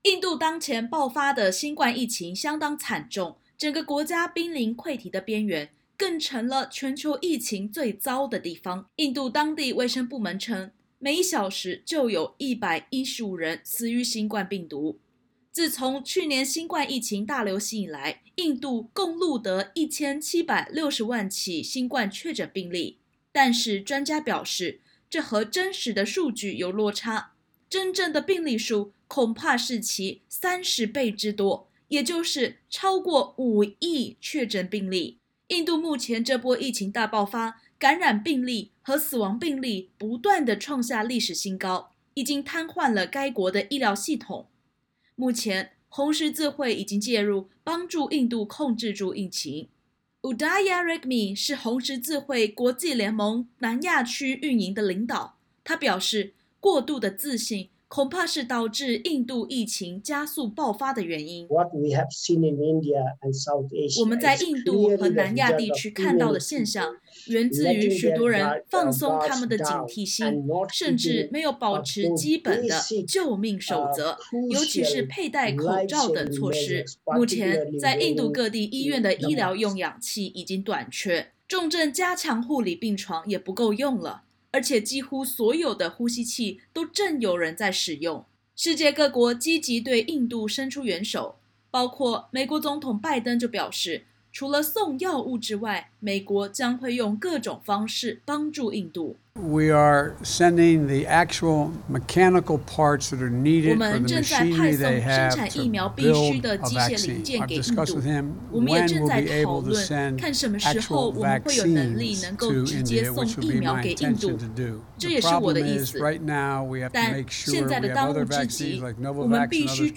新冠疫情全球大流行目前情势有了翻转，就在美国开始放宽针对已接种疫苗者的口罩相关限令，同时间，因为变种病毒肆虐，印度成了全球疫情最严峻的国家。点击首图收听采访音频。